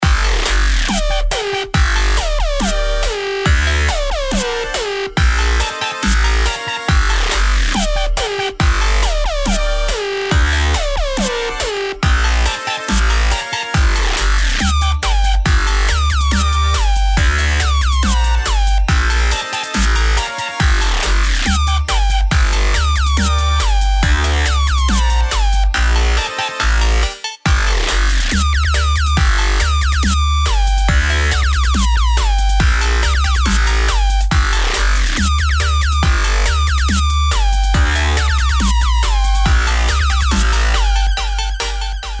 • Качество: 192, Stereo
Новый дабчик с глубин интрнета!